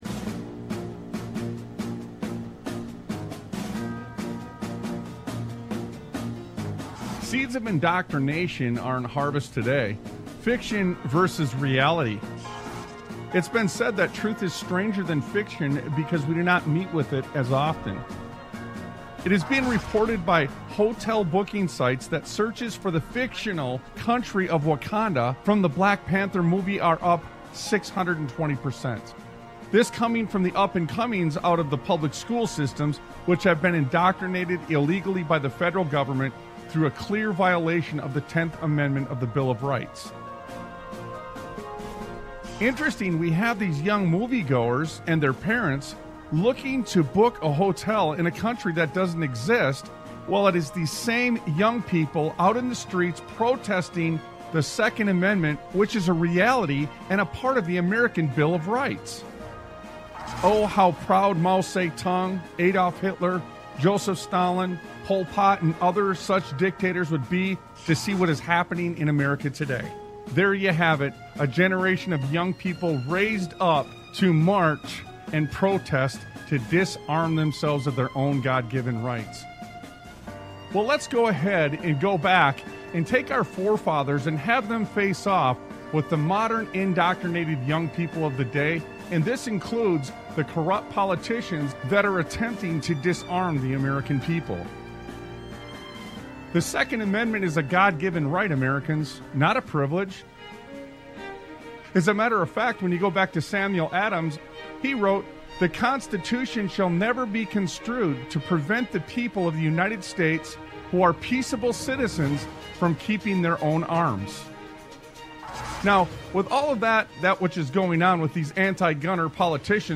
Talk Show Episode, Audio Podcast, Sons of Liberty Radio and If Not You, Then Who? If Not Now, Then When? on , show guests , about If Not You,Then Who,If Not Now,Then When, categorized as Education,History,Military,News,Politics & Government,Religion,Christianity,Society and Culture,Theory & Conspiracy